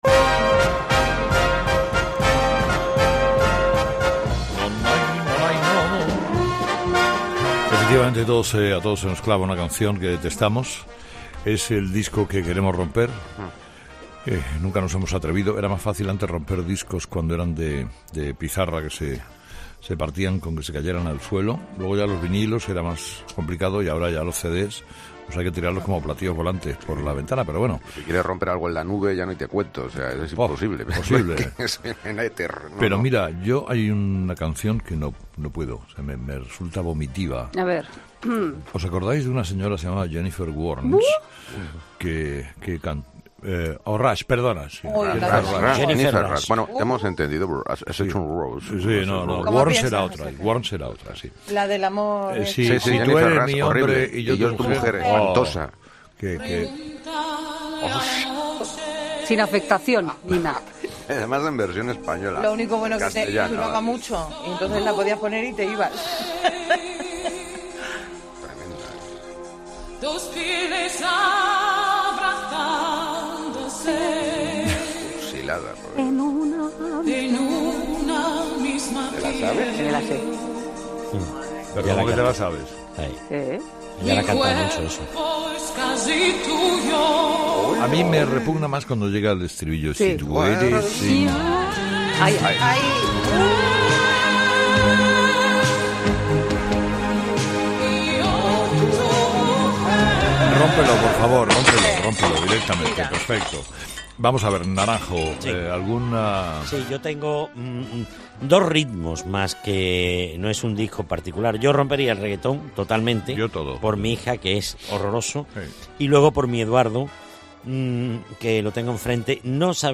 'Los Fósforos' es el espacio en el que Carlos Herrera habla de tú a tú con los ciudadanos, en busca de experiencias de vida y anécdotas deliciosas, que confirman el buen humor y cercanía de los españoles.